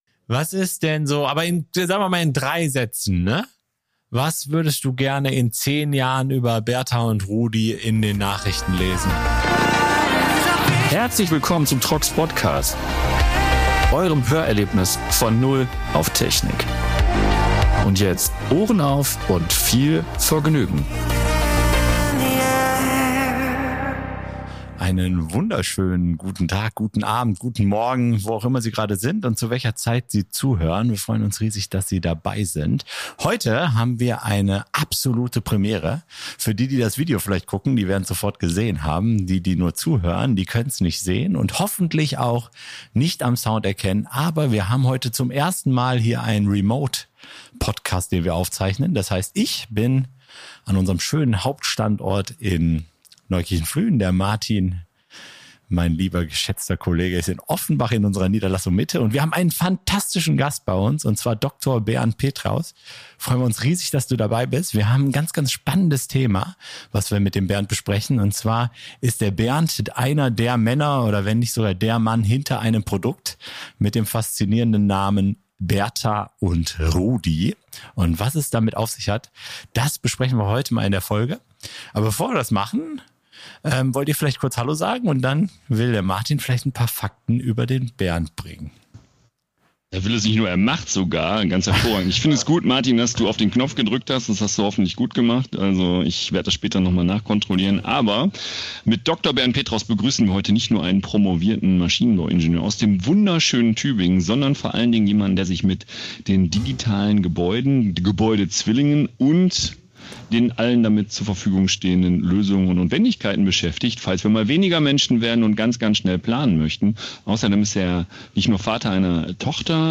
Premiere bei Von Null auf Technik: Unser erster Remote-Podcast ist live!